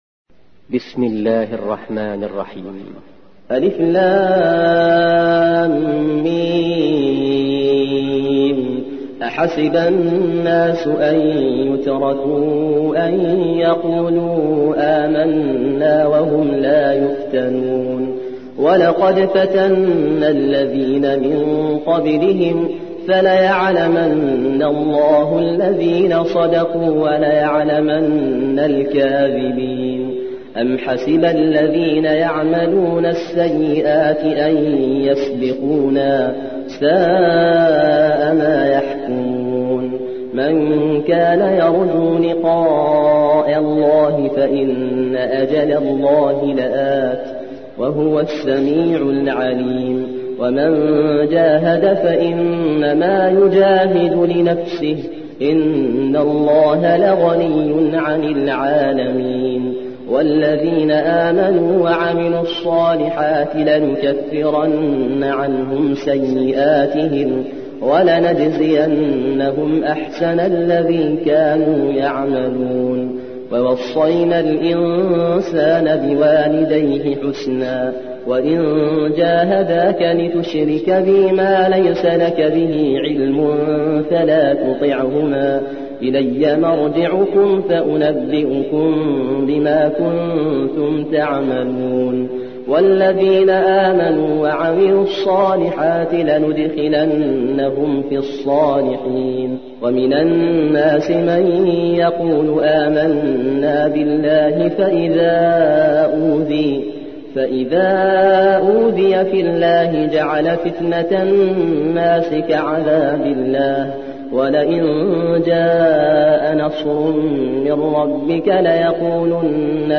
29. سورة العنكبوت / القارئ